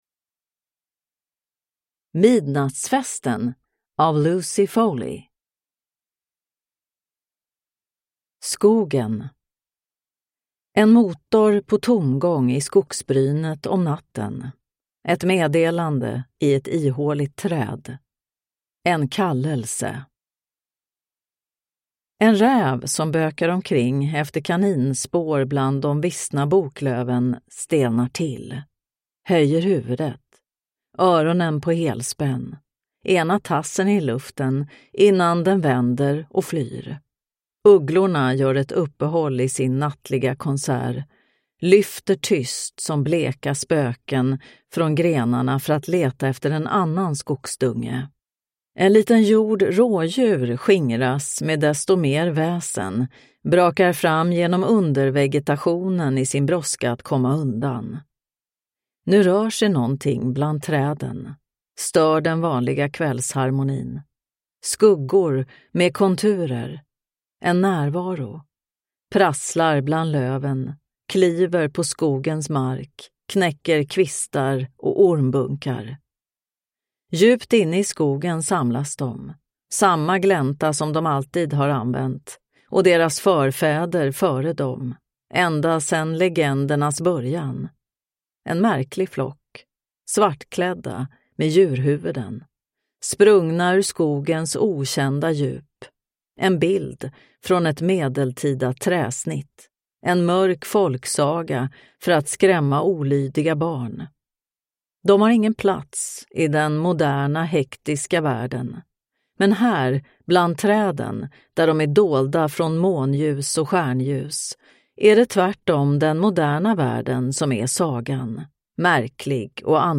Midnattsfesten (ljudbok) av Lucy Foley